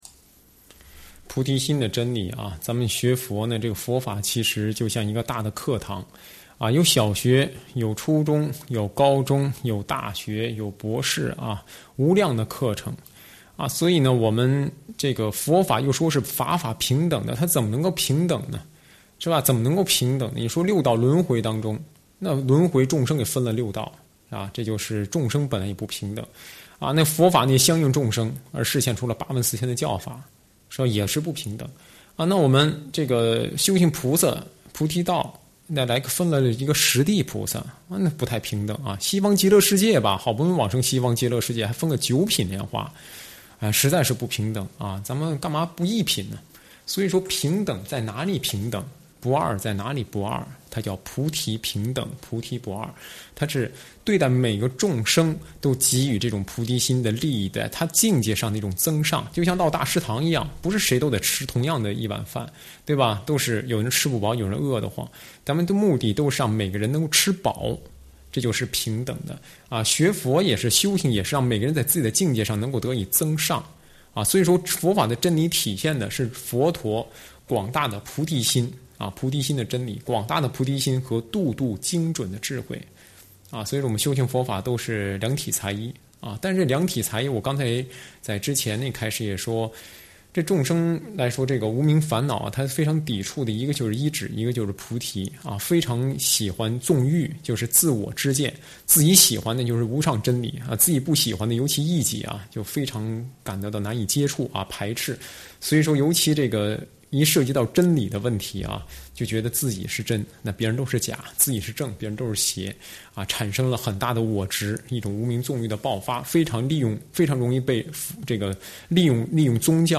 上师语音开示